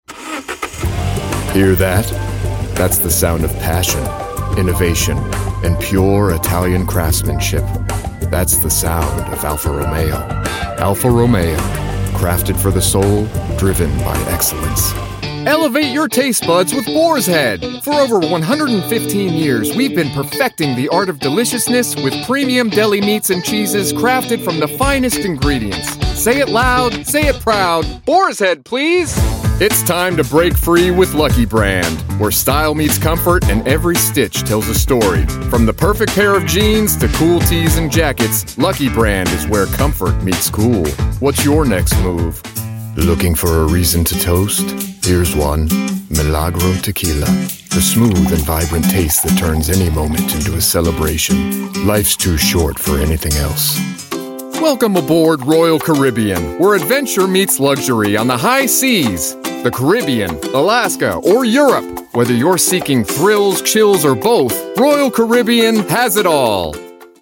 Commercial Demo 00:00 / 01:07 Download Character Demo 00:00 / 01:34 Download SOLD!
(Makes for quite the neutral dialect... and makes me OLD.)